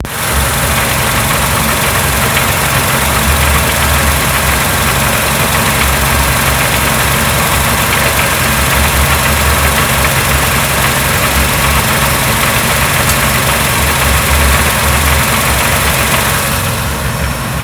Cinkavý zvuk na volnoběh, převodovka ?
motor.mp3